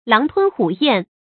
注音：ㄌㄤˊ ㄊㄨㄣ ㄏㄨˇ ㄧㄢˋ
狼吞虎咽的讀法